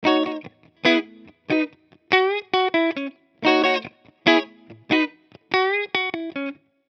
标签： 140 bpm Hip Hop Loops Guitar Electric Loops 590.73 KB wav Key : Unknown
声道单声道